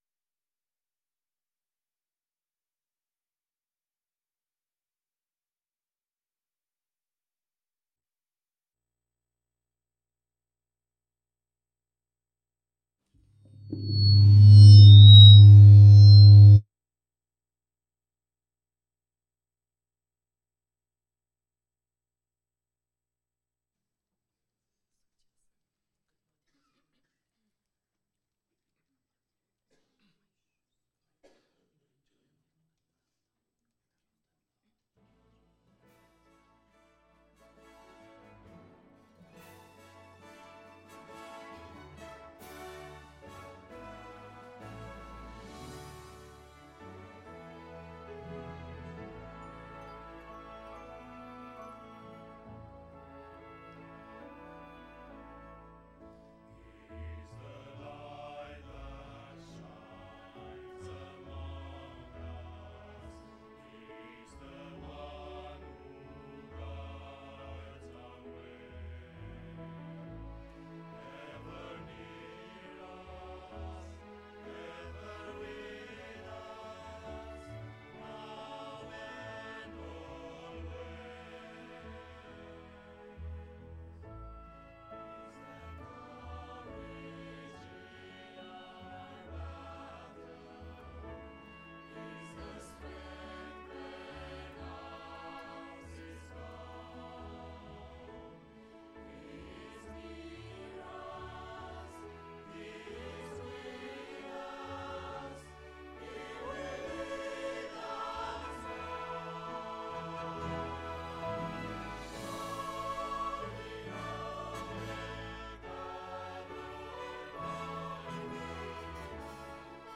Tuesday Evenings Presentation from Camp Meeting 2024